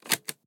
lockOpen.ogg